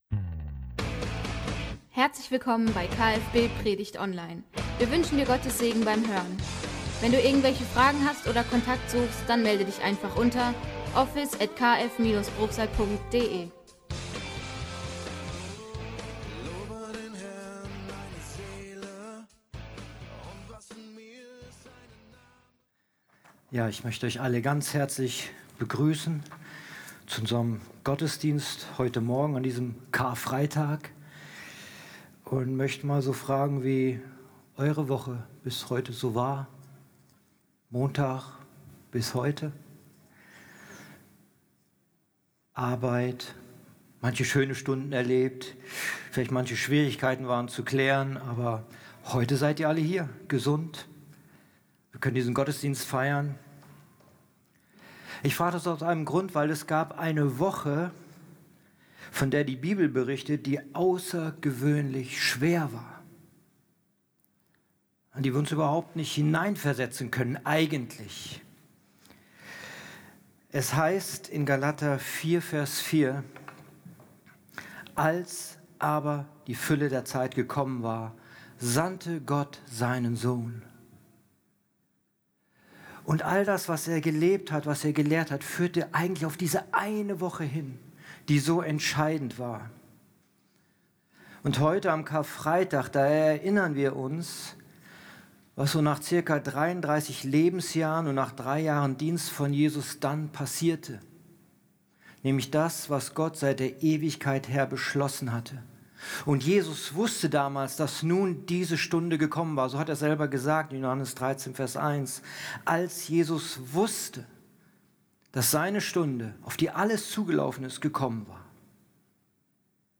All dem gehen wir heute an Karfreitag nach – jeweils unterbrochen und begleitet durch Lieder, die das Geschehene aufgreifen.